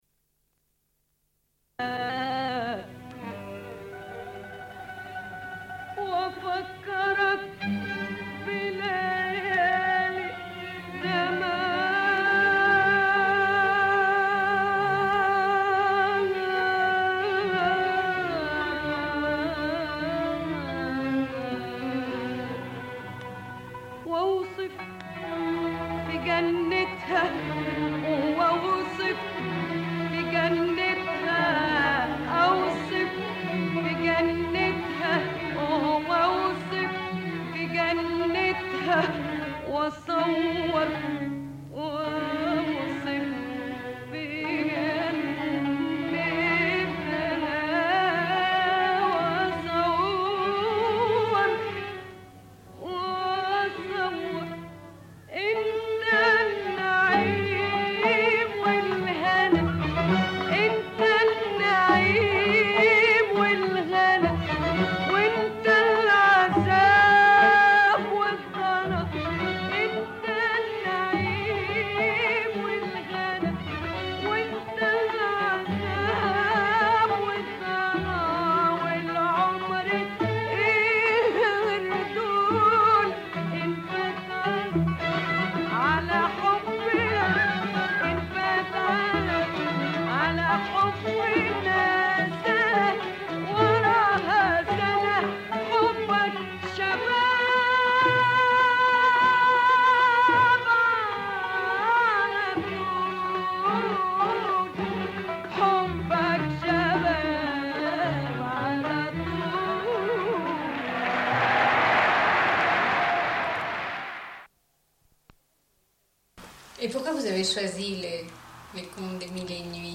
Une cassette audio, face B47:29